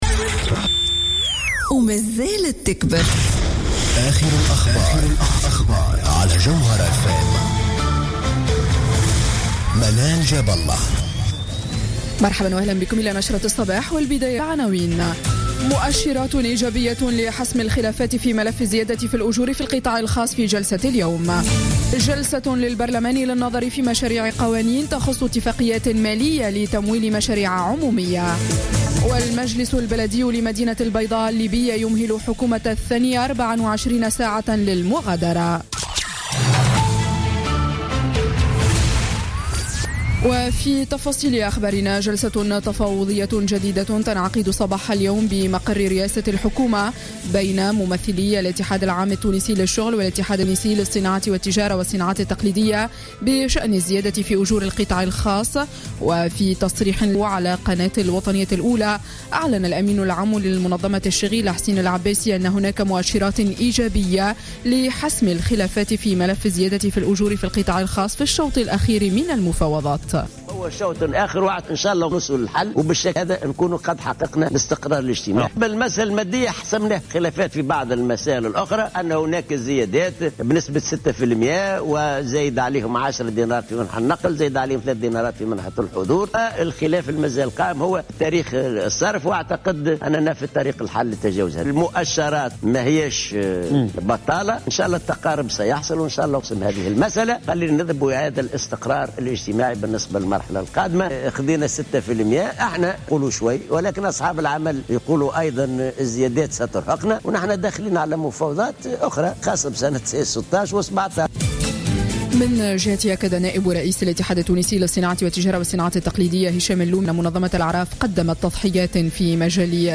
نشرة أخبار السابعة صباحا ليوم الثلاثاء 19 جانفي 2016